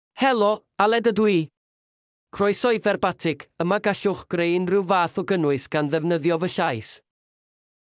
AledMale Welsh AI voice
Aled is a male AI voice for Welsh (United Kingdom).
Voice sample
Listen to Aled's male Welsh voice.
Aled delivers clear pronunciation with authentic United Kingdom Welsh intonation, making your content sound professionally produced.